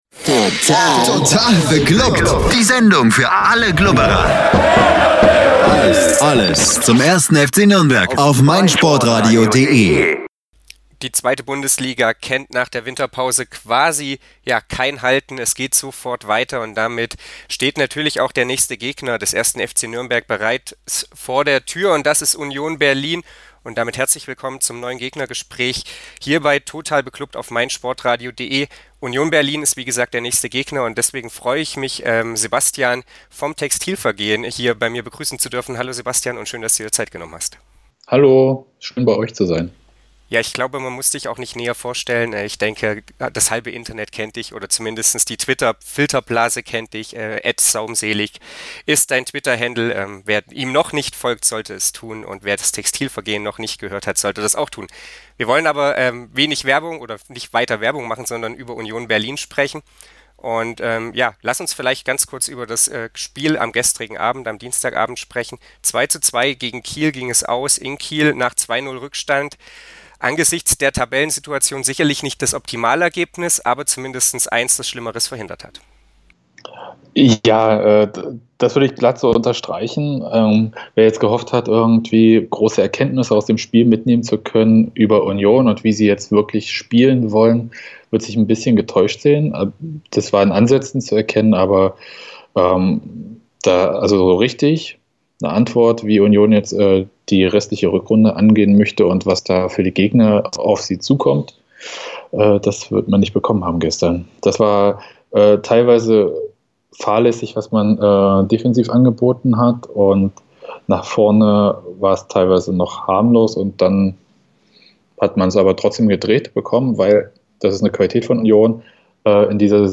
Gegnergespräch Union Berlin ~ Total beglubbt Podcast